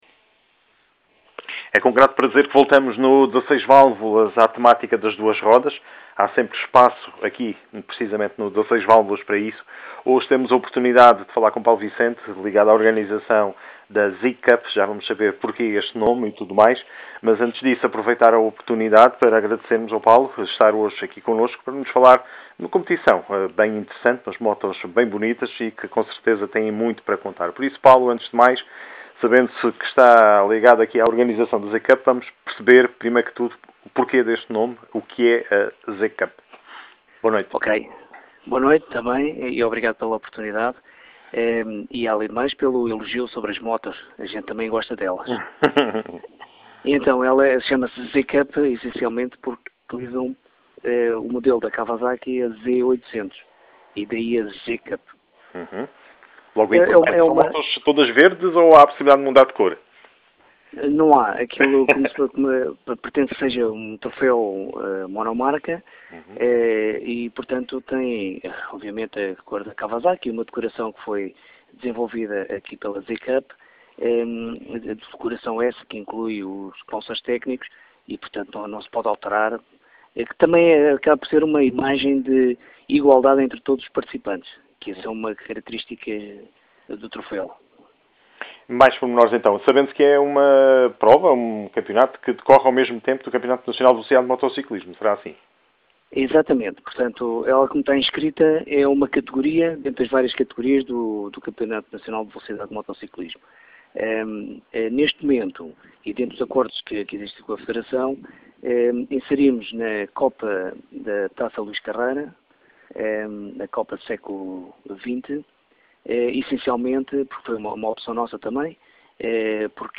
Entrevista para a 16Válvulas sobre a ZCUP PT - ZCUP PT
Em boa conversa tivemos na Radio 16Valvulas onde falámos sobre a copa ZCUP e tudo o que a ela diz respeito.